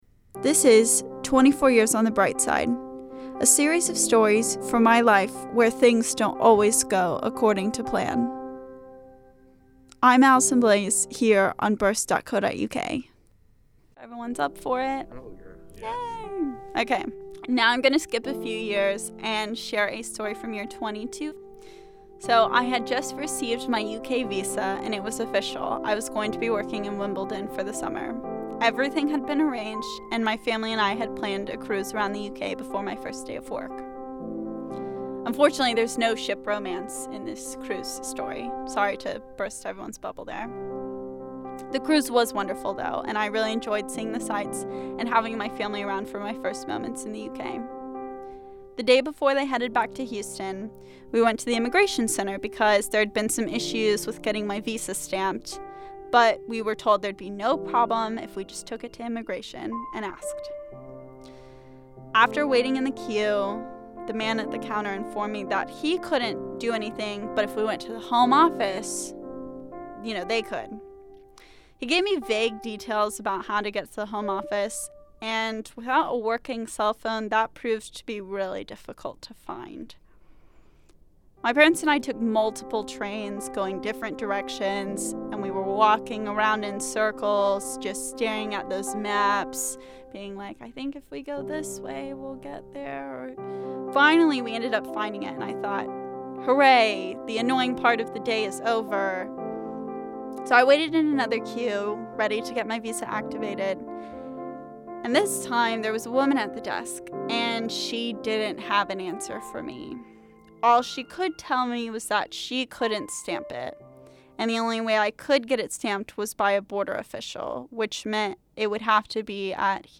’24 Years On The Brightside’ was recorded in front of a live audience.